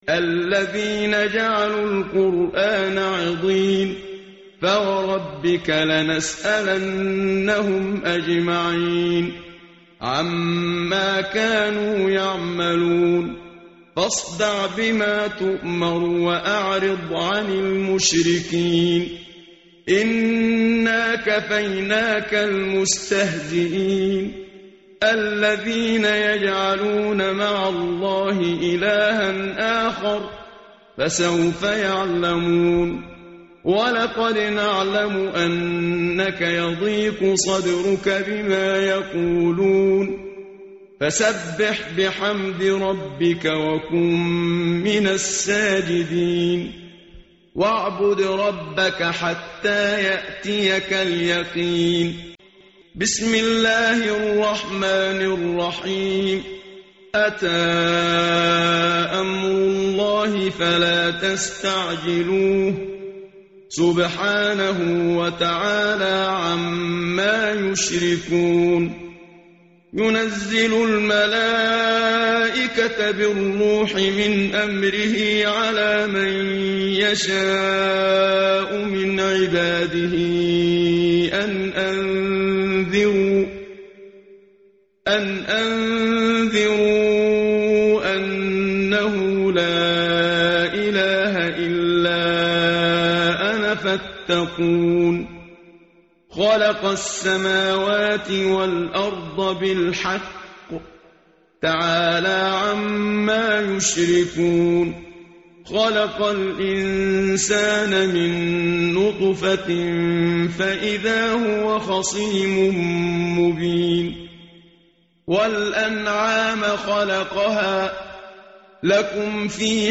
متن قرآن همراه باتلاوت قرآن و ترجمه
tartil_menshavi_page_267.mp3